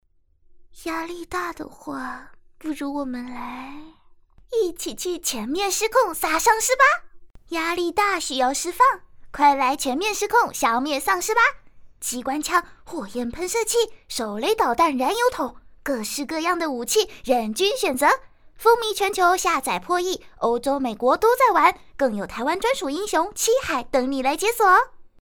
游戏文案解说